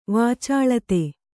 ♪ vācāḷate